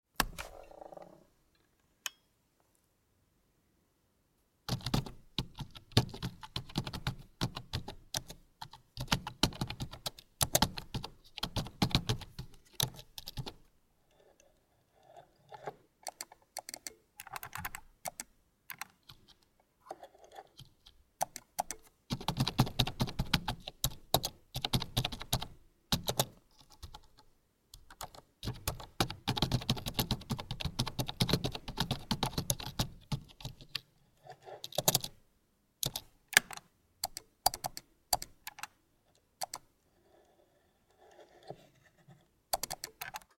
Apple iBook Duo 230